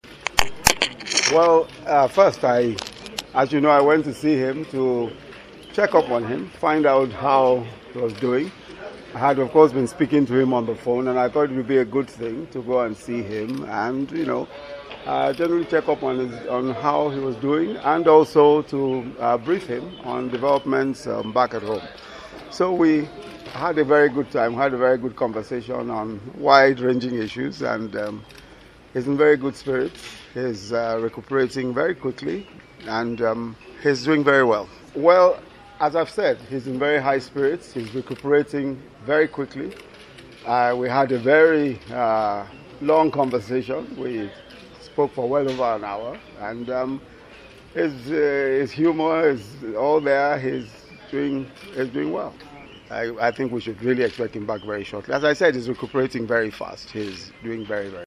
Osinbajo stated this on Wednesday while answering reporters’ questions shortly before the commencement of the Federal Executive Council (FEC) meeting at the Presidential Villa, Abuja.